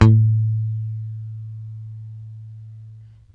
Index of /90_sSampleCDs/Roland L-CDX-01/BS _E.Bass 2/BS _Rock Bass